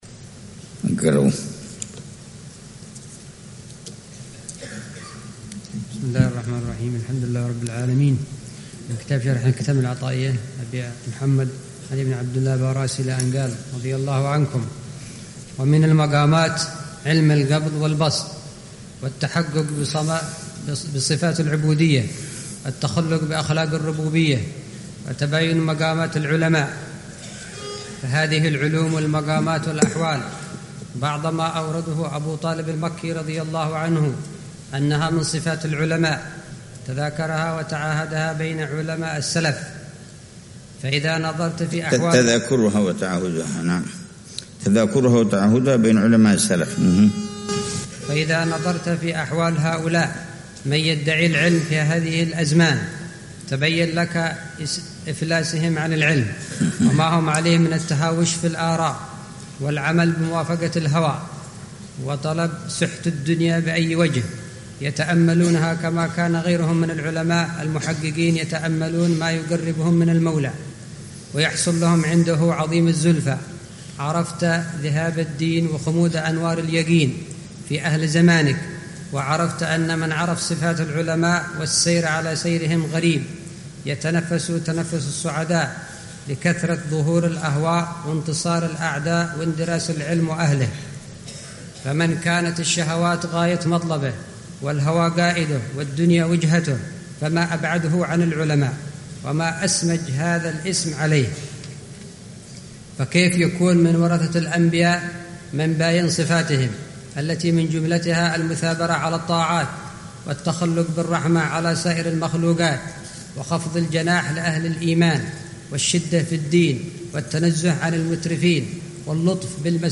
شرح العلامة الحبيب عمر بن محمد بن حفيظ لكتاب شرح الحِكم العطائية للشيخ علي بن عبدالله با راس رحمه الله تعالى، ضمن فعاليات الدورة التعليمية الح